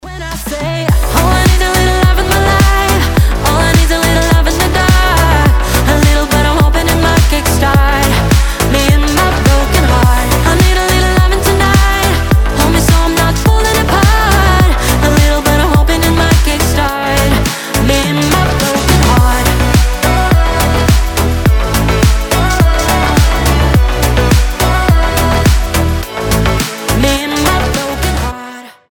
• Качество: 320, Stereo
женский голос
Cover
ремиксы